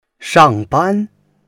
shang4ban1.mp3